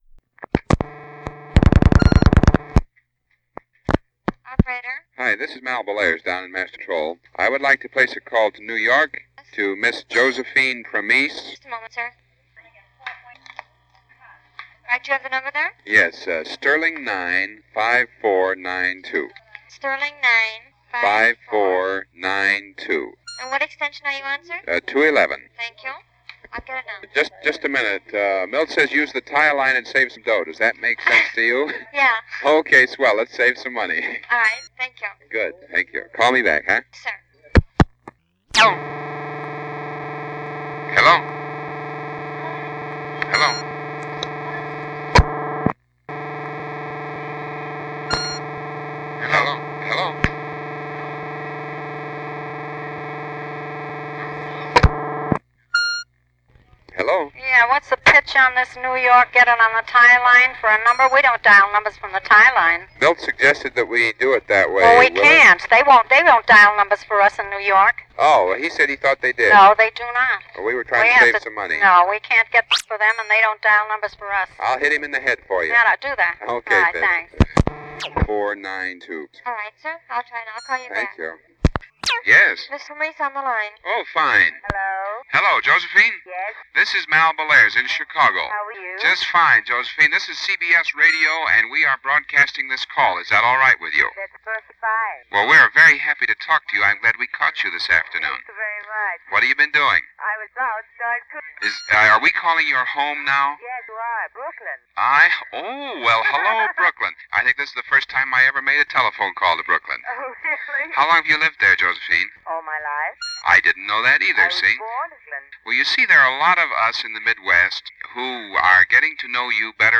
Long distance phone call
Short, personable, and very much of its time.